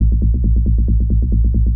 K-5 Bassline.wav